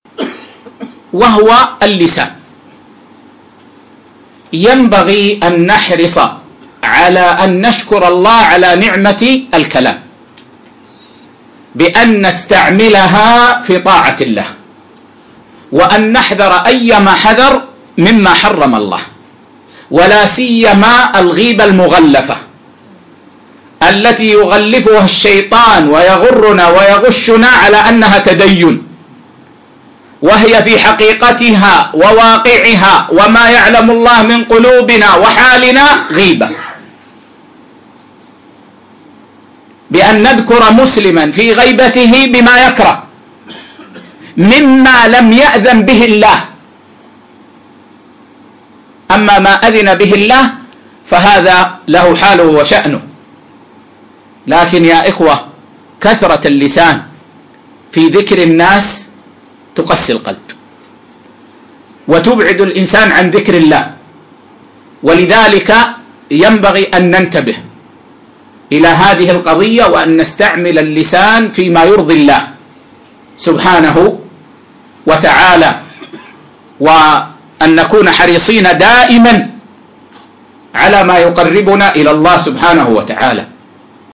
مقتطف من اللقاء المفتوح